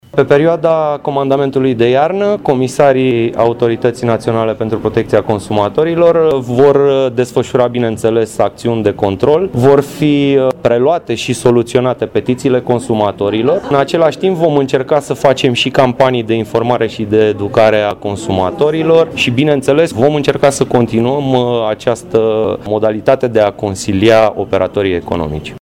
Anunțul a fost făcut la Brașov.
Autoritatea Națională pentru Protecția Consumatorilor a anunțat în Poiana Brașov, prin vocea vicepreședintelui instituției, Emil Niță, instituirea, începând cu 17 decembrie, a Comandamentului de Iarnă 2017, în domeniul turismului.